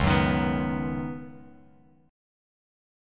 Db9sus4 chord